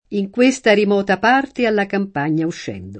remoto [rem0to] agg. — antiq. rimoto [rim0to]: in questa Rimota parte alla campagna uscendo [